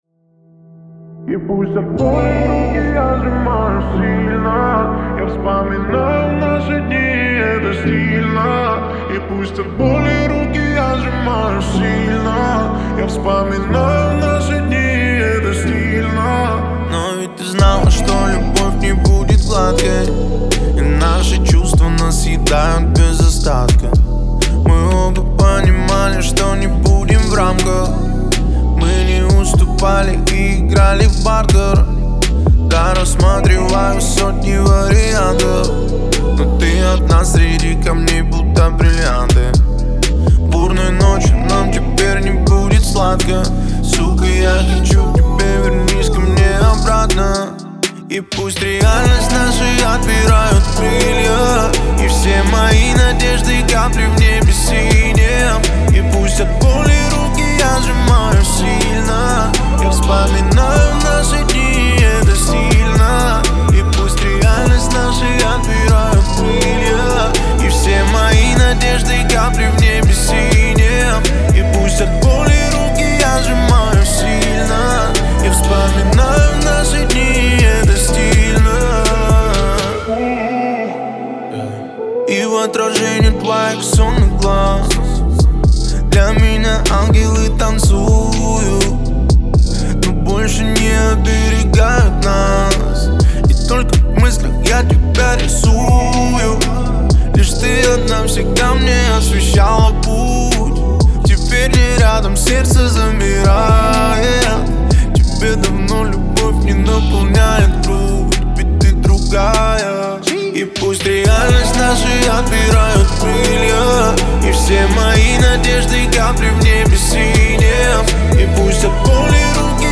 яркая и энергичная композиция